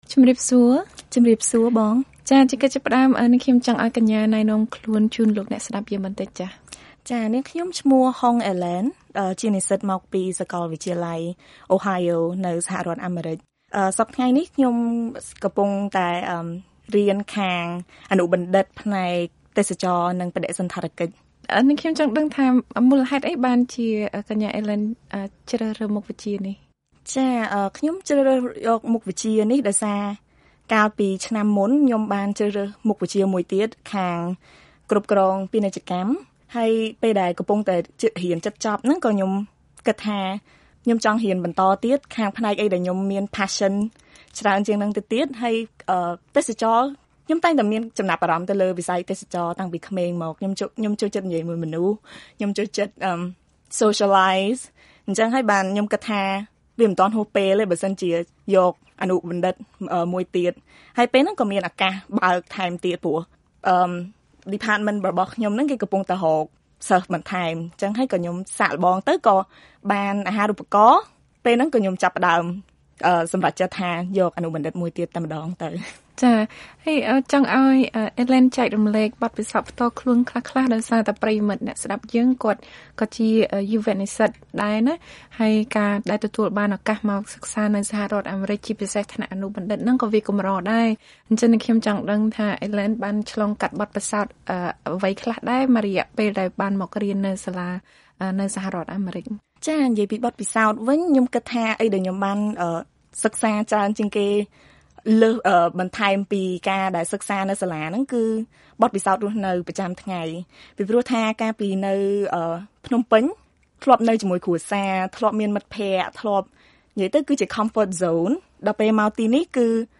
បទសម្ភាសន៍ VOA